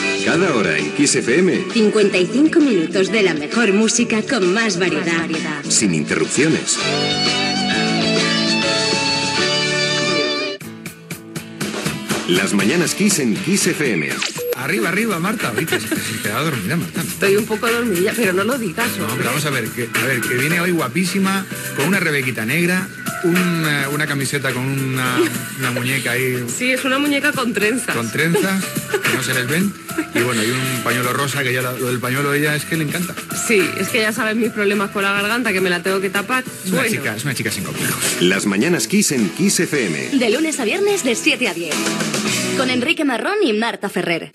Promoció de "Las mañanas Kiss"